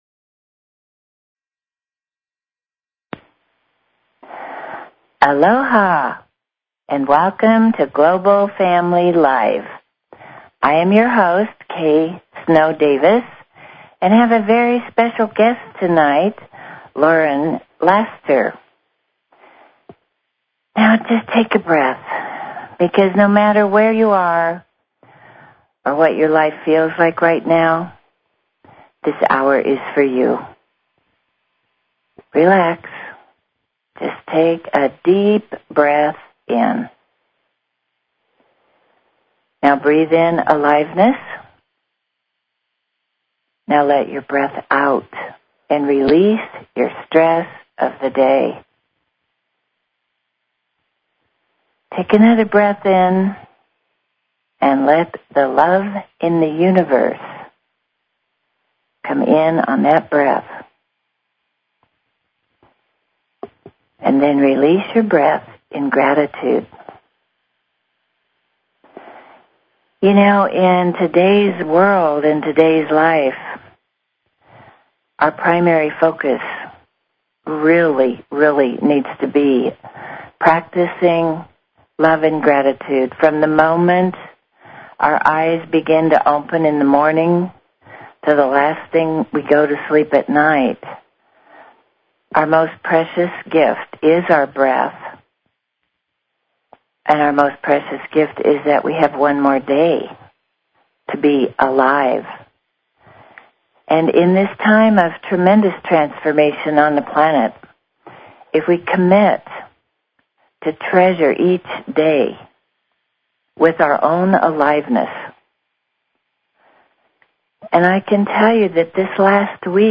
Talk Show Episode, Audio Podcast, Global_Family_Live and Courtesy of BBS Radio on , show guests , about , categorized as